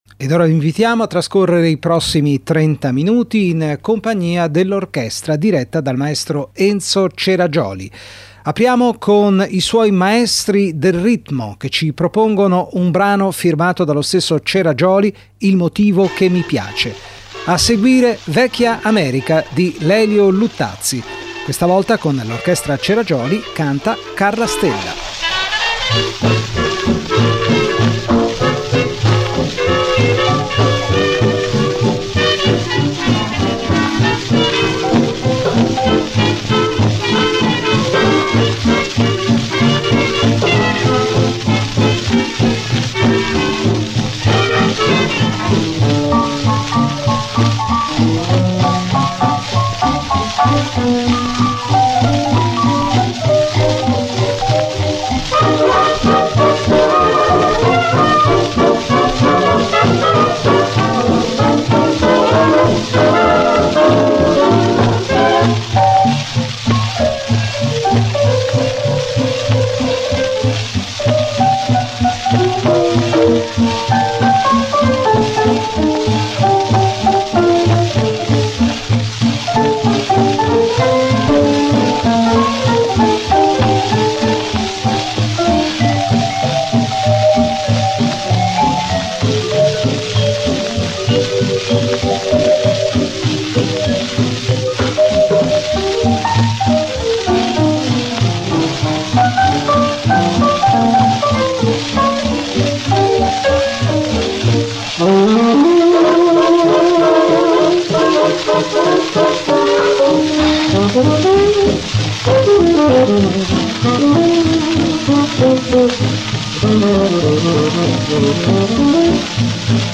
Orchestra da ballo